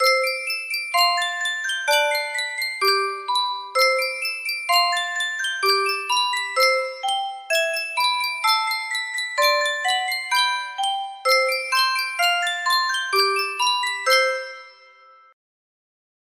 Sankyo Music Box - Far Above Cayuga's Waters YGD music box melody
Full range 60